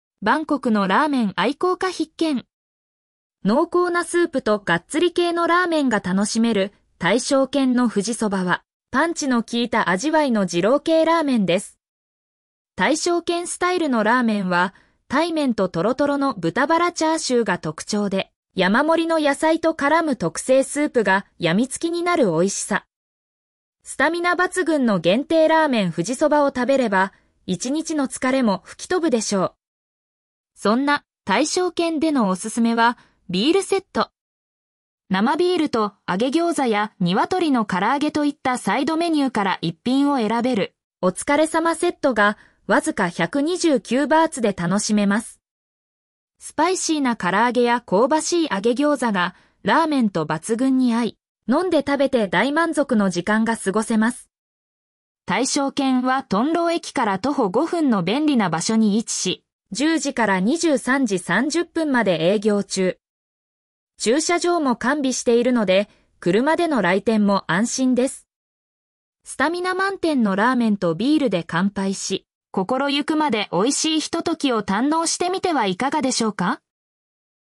読み上げ